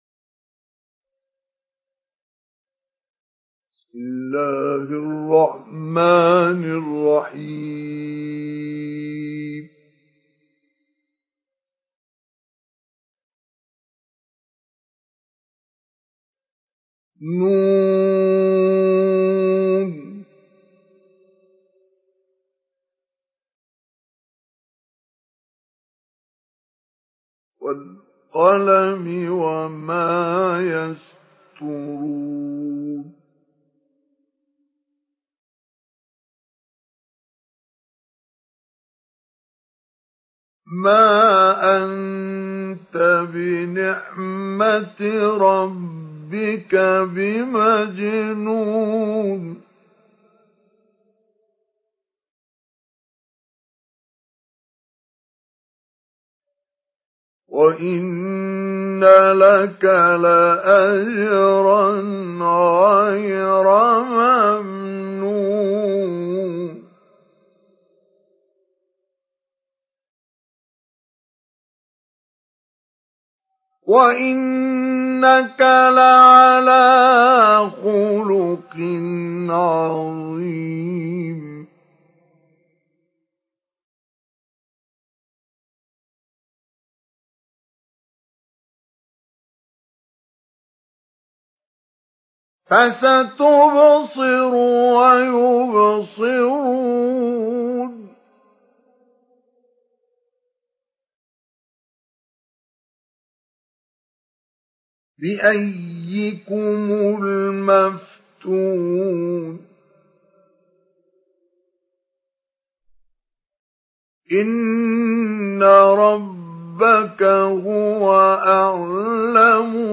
Surat Al Qalam Download mp3 Mustafa Ismail Mujawwad Riwayat Hafs dari Asim, Download Quran dan mendengarkan mp3 tautan langsung penuh
Download Surat Al Qalam Mustafa Ismail Mujawwad